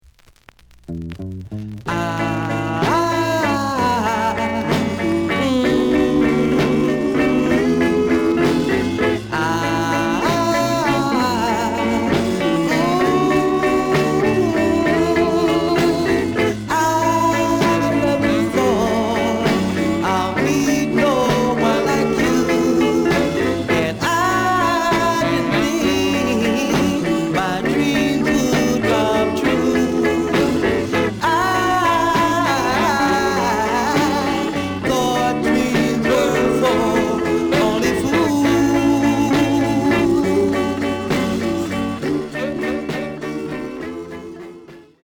The listen sample is recorded from the actual item.
●Format: 7 inch
●Genre: Rhythm And Blues / Rock 'n' Roll